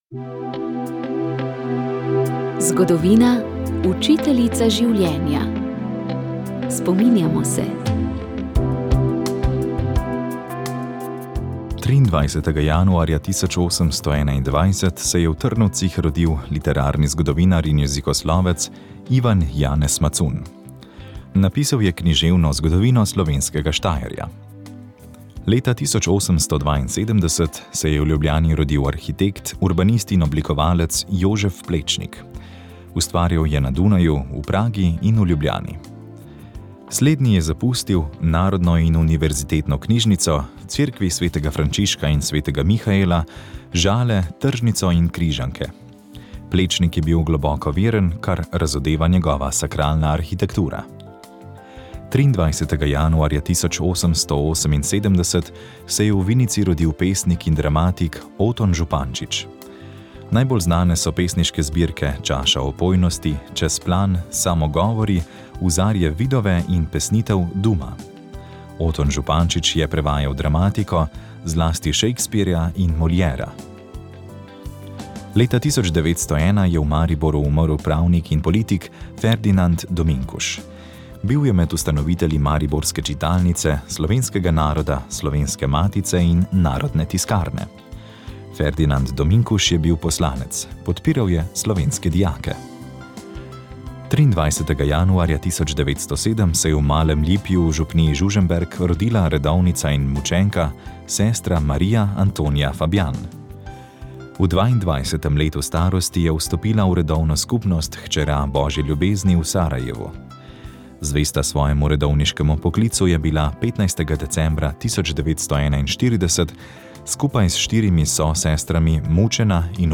Rožni venec
Molili so bogoslovci.